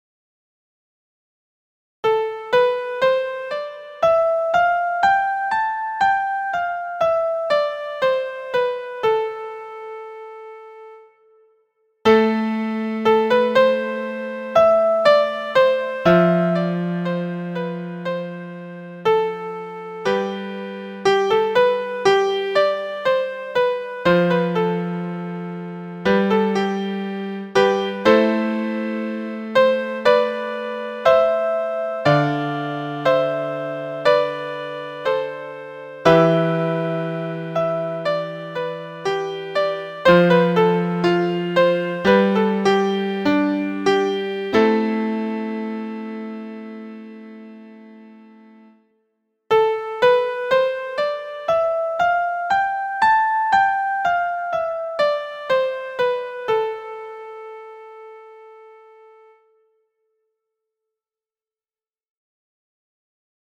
AEOLIAN
Aeolian.mp3